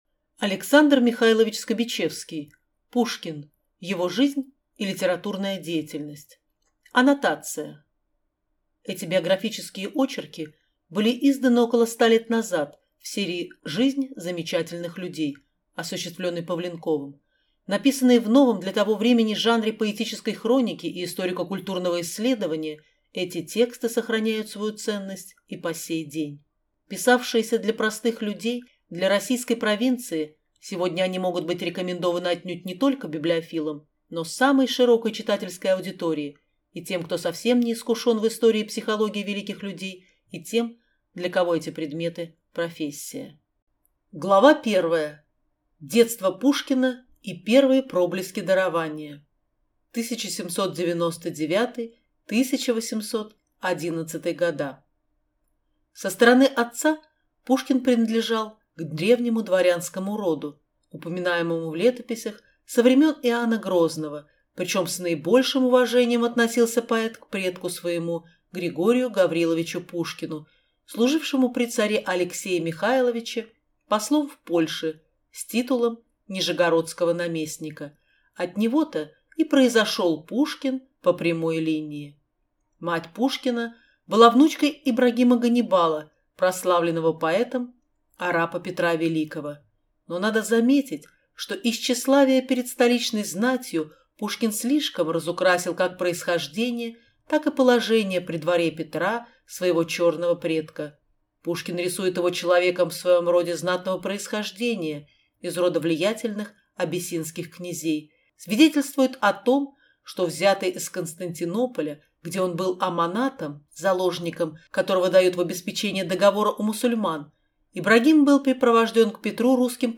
Аудиокнига Пушкин. Его жизнь и литературная деятельность | Библиотека аудиокниг